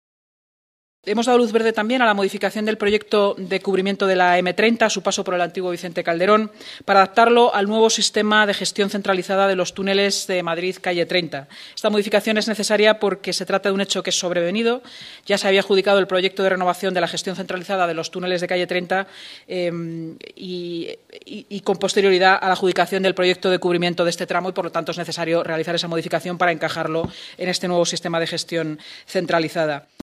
Nueva ventana:La delegada de Seguridad y Emergencias y portavoz municipal, Inmaculada Sanz